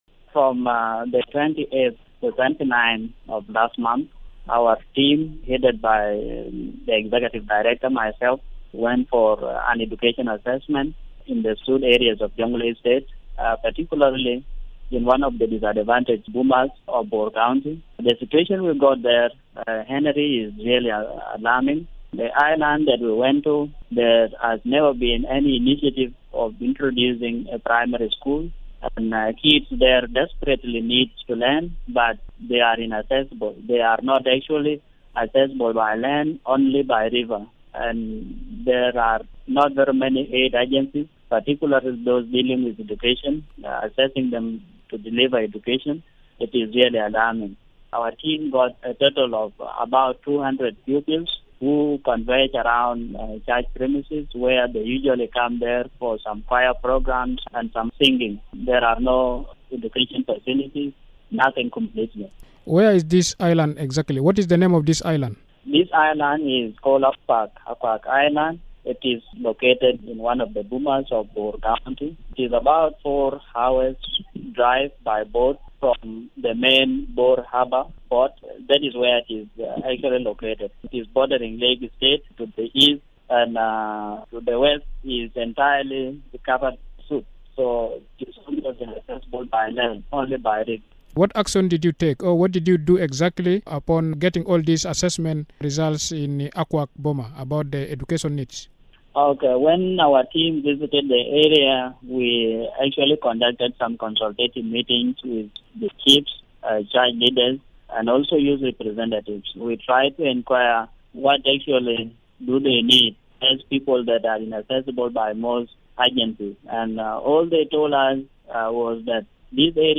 Radio Miraya